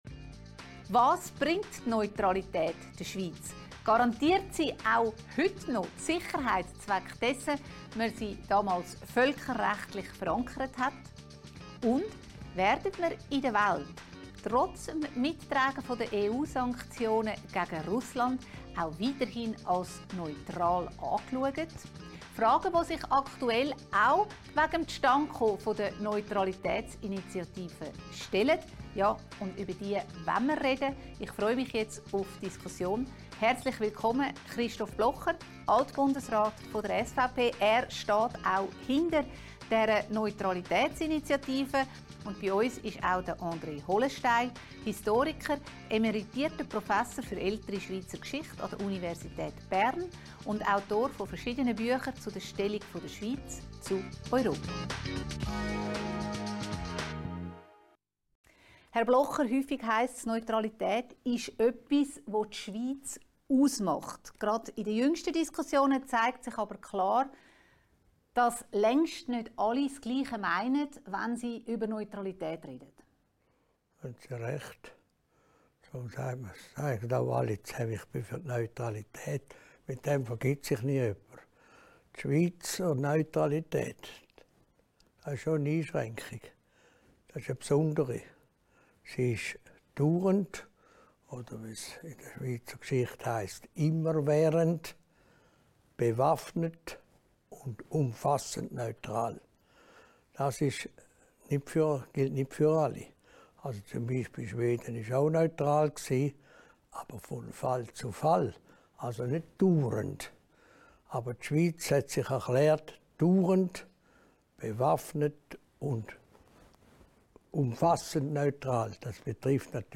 Das Konkret mit dem Streitgespräch zwischen Alt-Bundesrat Christoph Blocher